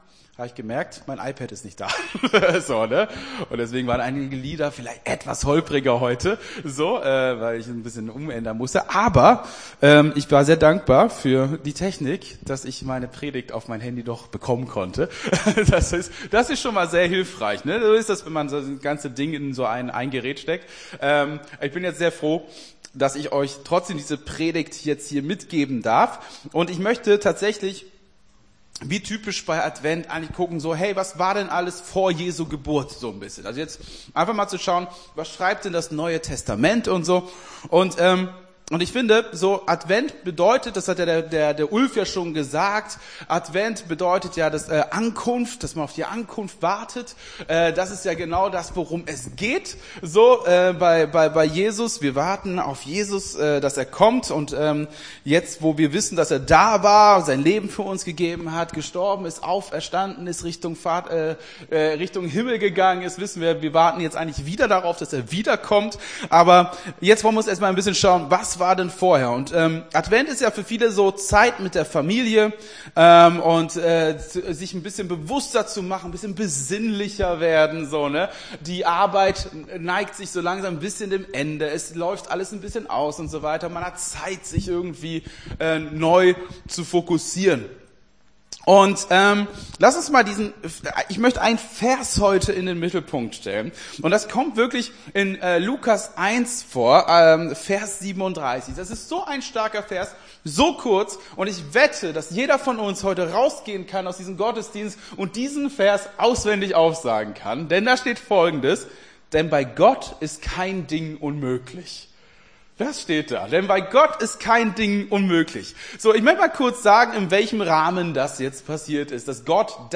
Gottesdienst 03.12.23 - FCG Hagen